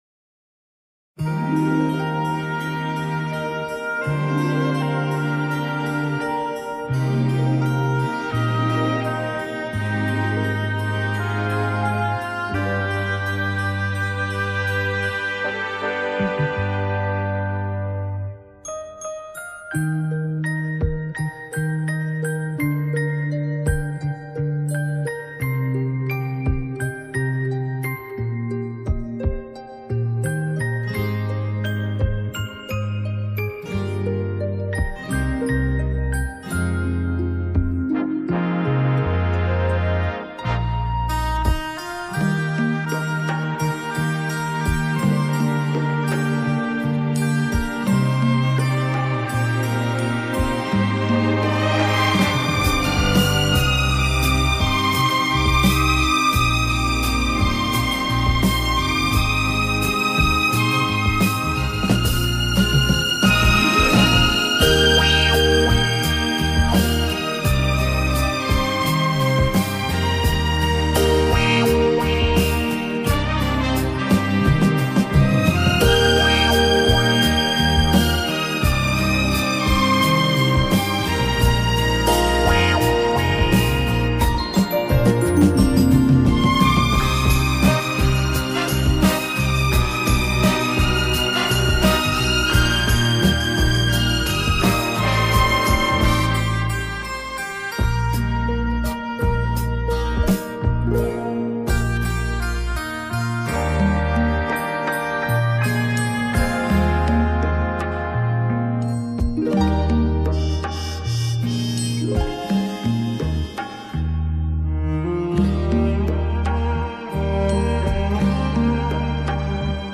Genre:Easy Listening